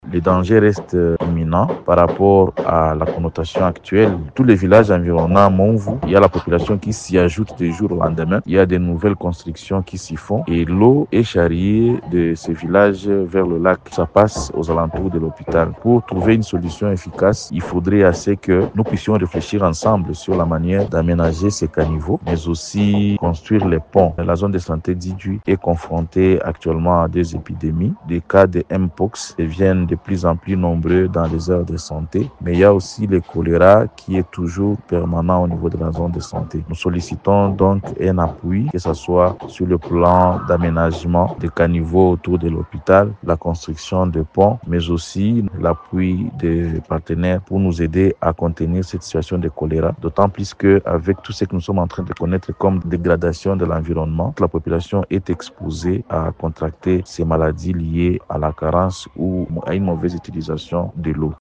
ELEMENT-IDJWI-FR.mp3